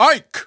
The announcer saying Ike's name in English and Japanese releases of Super Smash Bros. Brawl.
Category:Ike (SSBB) Category:Announcer calls (SSBB) You cannot overwrite this file.
Ike_English_Announcer_SSBB.wav